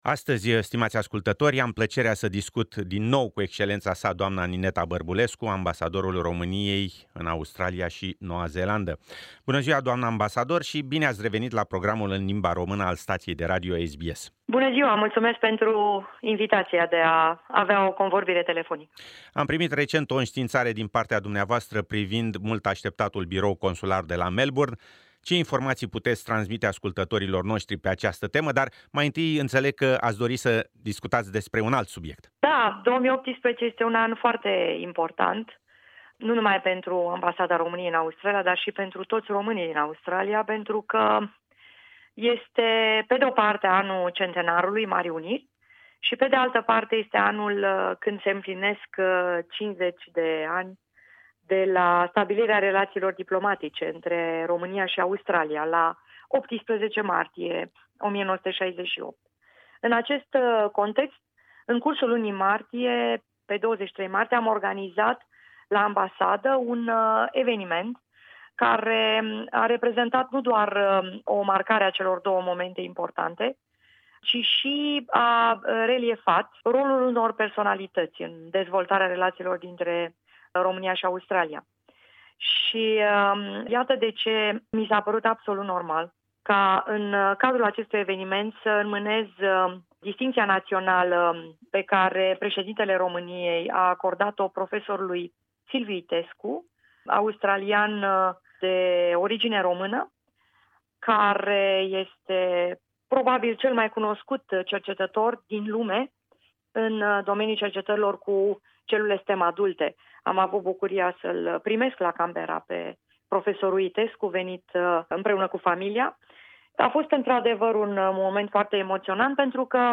Interviu cu Ambasadorul Romaniei in Australia si Noua Zeelanda, Excelenta Sa Nineta Barbulescu
interviu_nineta_barbulescu.mp3